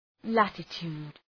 Shkrimi fonetik {‘lætə,tu:d}
latitude.mp3